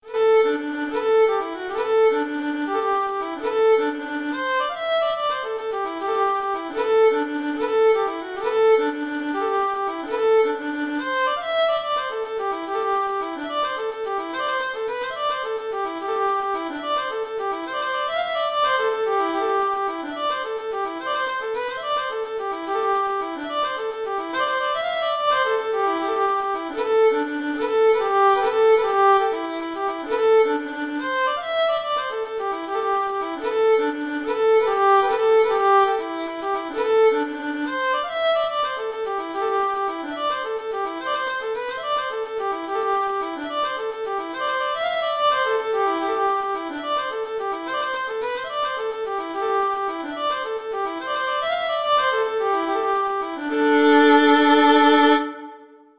A well-known traditional Irish folk tune, also known as Dinny Delany’s, The Hag At The Kiln, The Hag In The Kiln, Old Hag At The Kiln, The Old Hag At The Kiln, The Old Hag In The Kiln, The Old Hag.
Arrangement for Violin solo
D major (Sounding Pitch) (View more D major Music for Violin )
Poco meno mosso .=72
Violin  (View more Intermediate Violin Music)
Traditional (View more Traditional Violin Music)